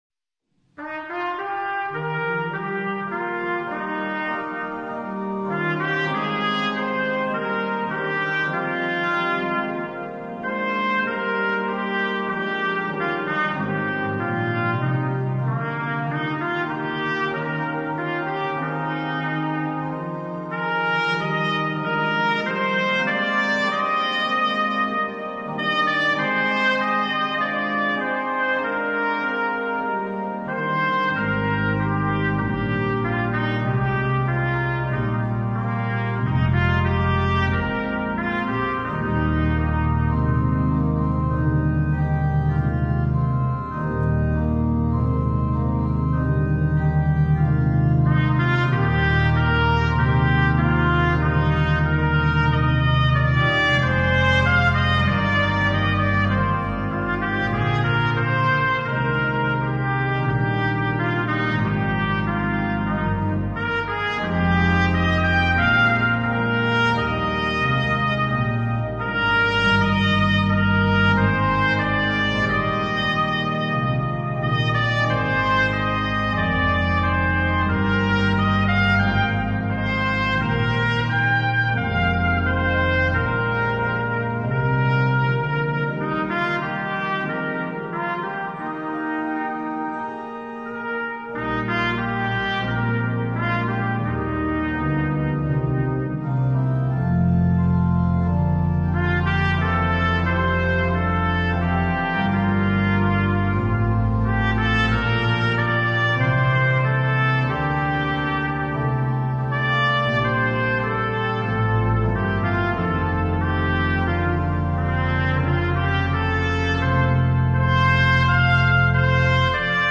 Voicing: Trumpet and Piano